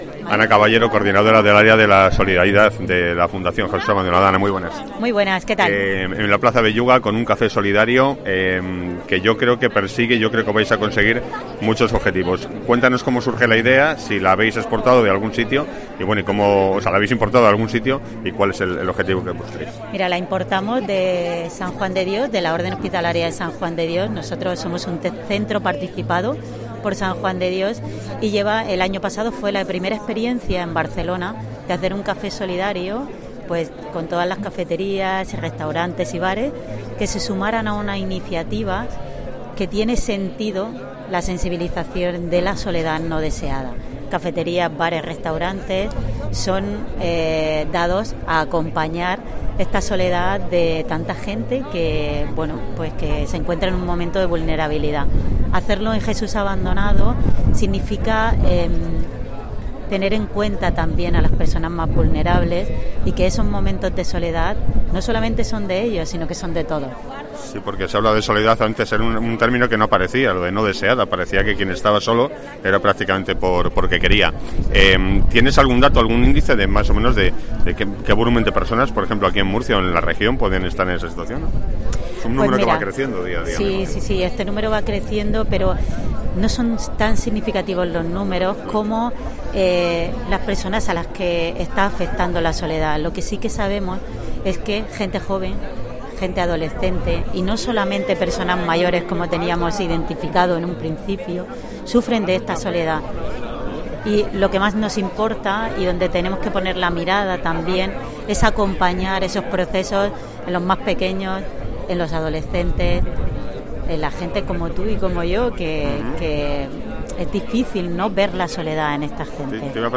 Jesús Abandonado organiza un café solidario en la plaza Belluga para sensibilizar sobre la soledad no deseada que sigue aumentando también entre los más jóvenes.